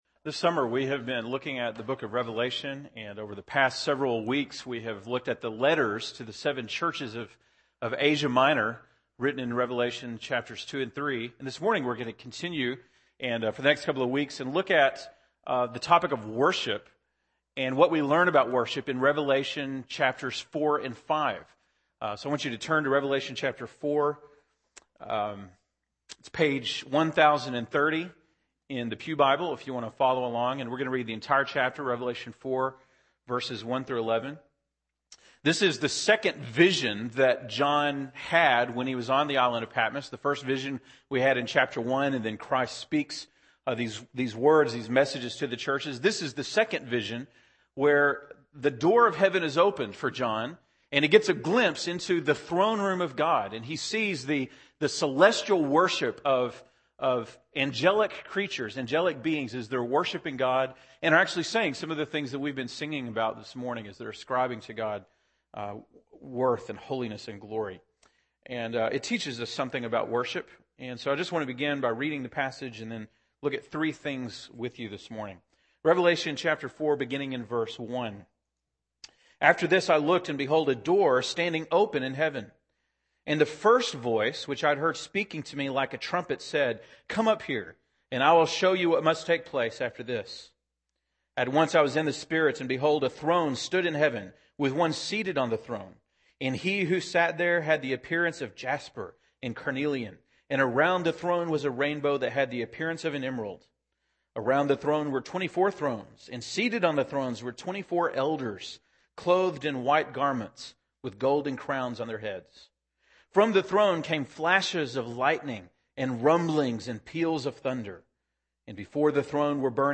August 8, 2010 (Sunday Morning)